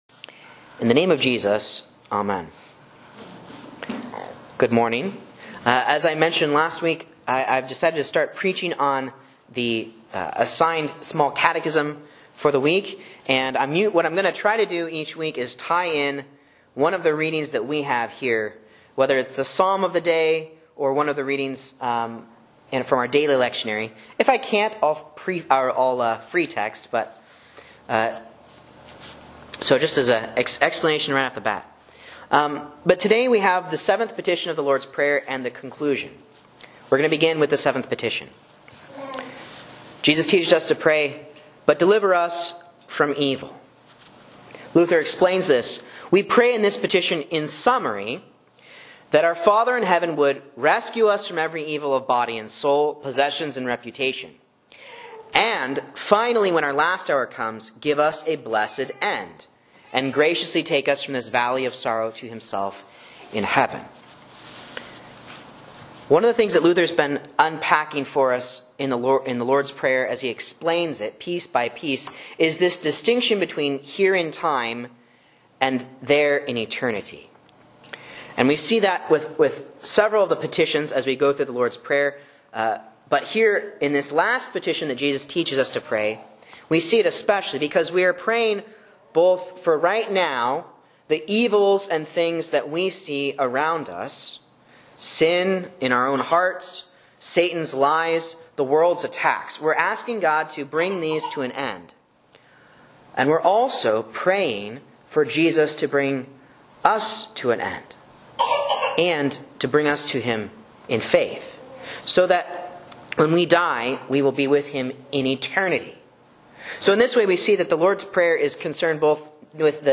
Sermon and Bible Class Audio from Faith Lutheran Church, Rogue River, OR
A Catechism Sermon on Psalm 121 for the 7th Petition & Conclusion to the Lord's Prayer